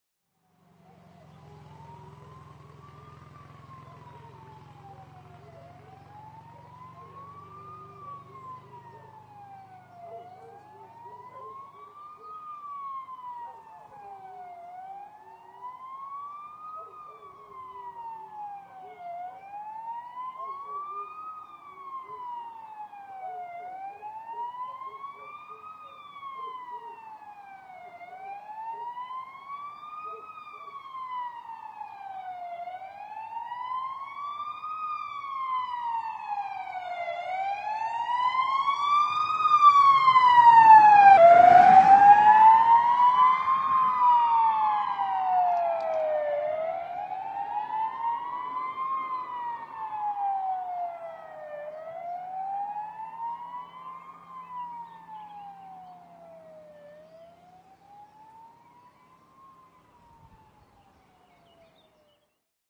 铃铛、哔哔声、信号记录 " 01097 紧急警报器 警察在行动
描述：现场记录了救援车快速驶过空旷地区的紧急警报器长长的淡入淡出和多普勒自然效果，不幸的是我必须剪掉2秒的过度录音，通过变焦H2后方3米的距离记录。
Tag: 多普勒 紧急情况下 现场记录 消防 公安 救护 报警器 警报器